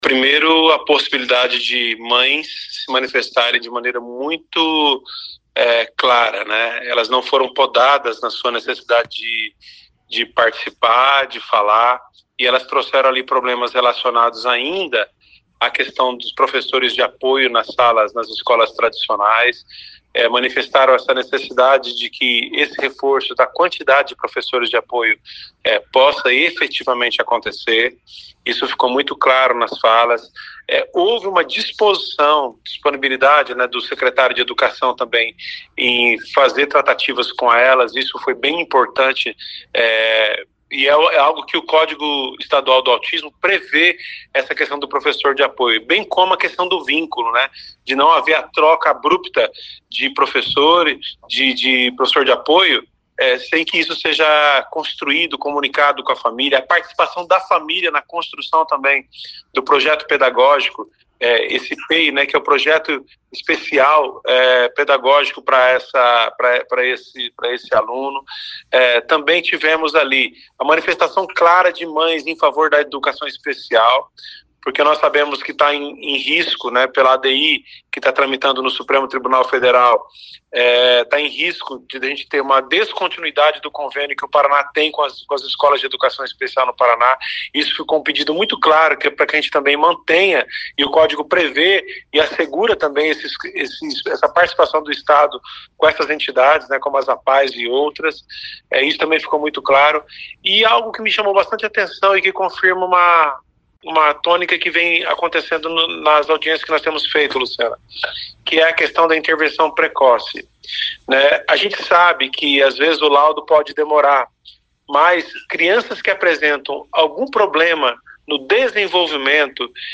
O deputado estadual Evandro Araújo, relator do Código do Autismo, diz que a audiência foi importante por revelar o que na prática precisa melhorar para o atendimento a crianças e adolescentes autistas.